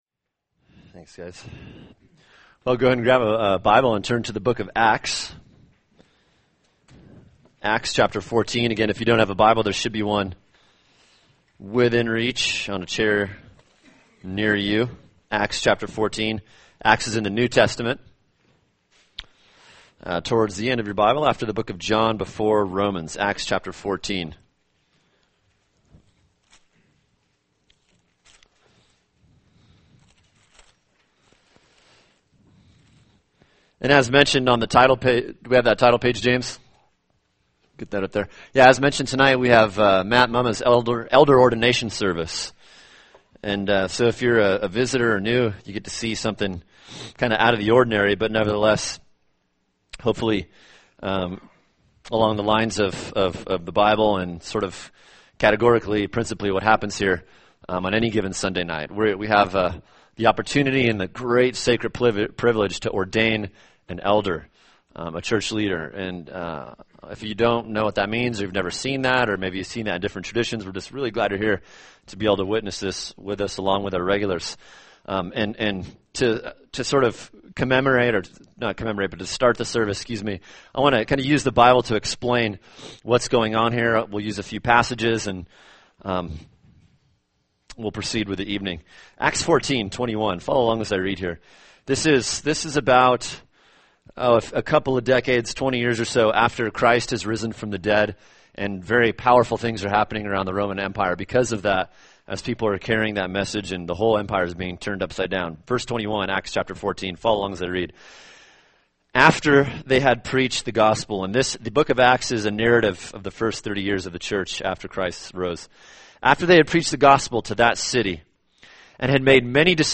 [sermon] 1 Thessalonians 2:1-12